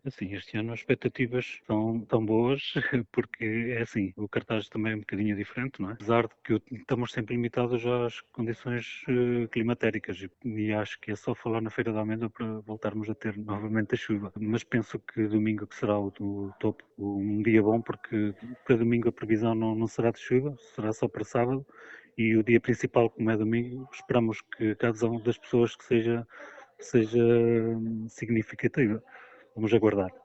O presidente da junta de freguesia de Amendoeira espera uma boa adesão da parte da população na mostra, com ótimas expetativas: